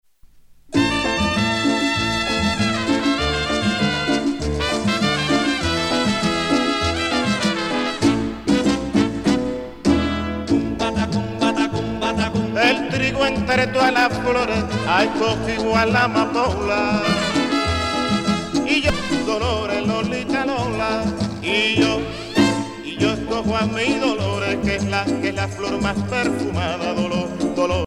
danse : veregua ;
Pièce musicale éditée